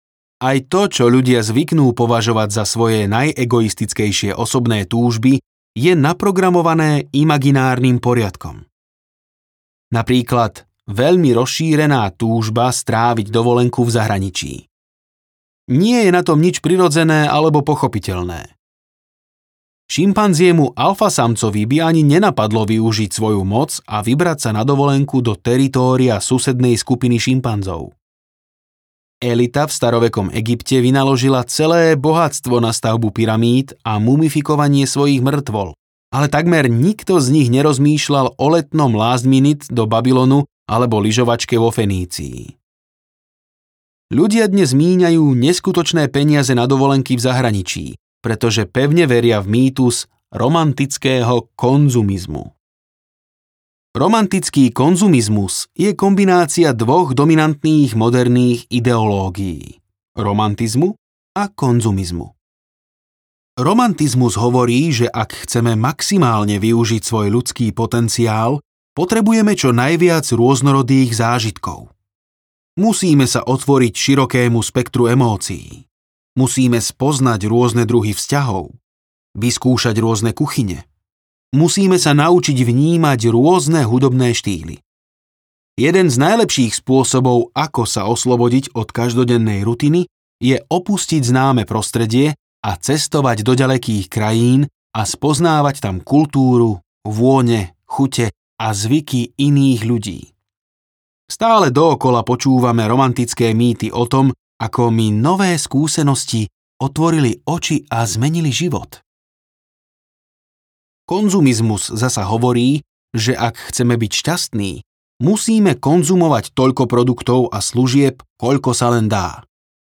Sapiens audiokniha
Ukázka z knihy